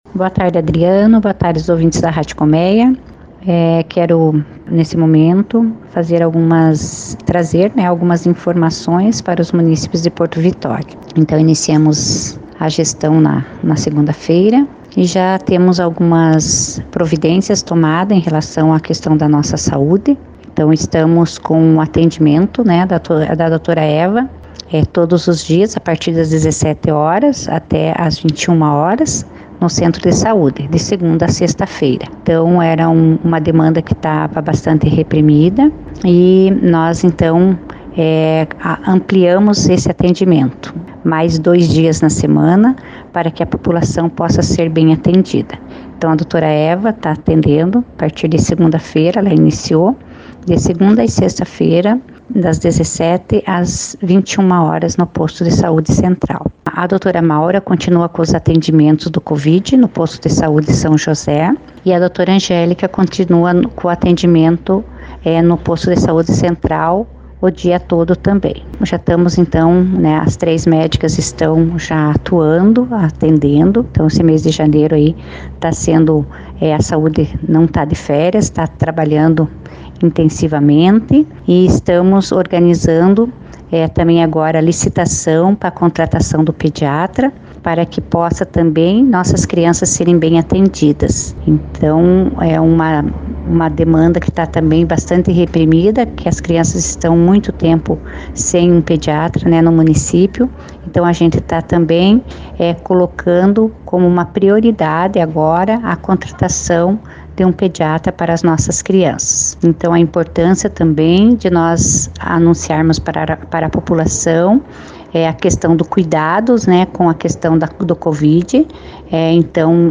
Iniciando os trabalhos na gestão 2021-2024, nessa segunda-feira, a prefeita Marisa Ilkiu anunciou providências na área da saúde.
MARISA-ILKIU-PREFEITA-PORTO-VITORIA.mp3